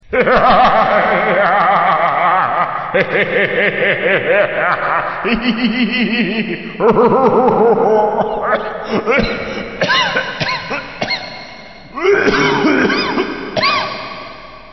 Risada Vilão Com Tosse
Vilão dá sua risada maldosa, mas se engasga e tosse no final.
risada-vilao-com-tosse.mp3